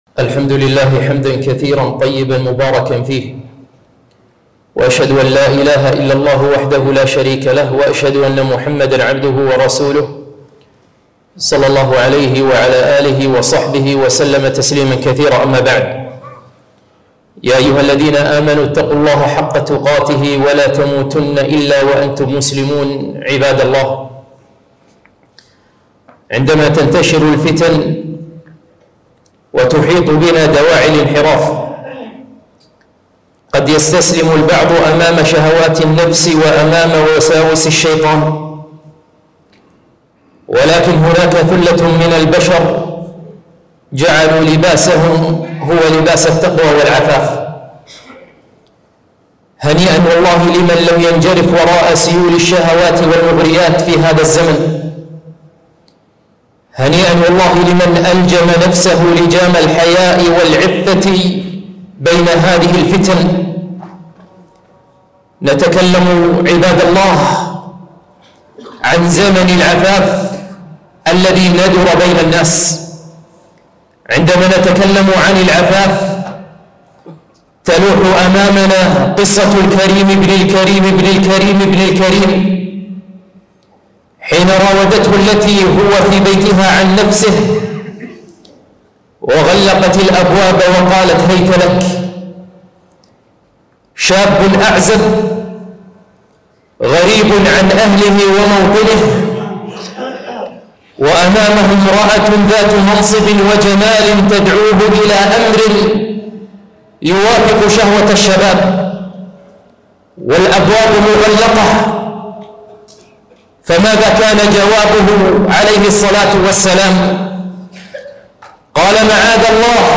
خطبة - العفاف وحفظ الفرج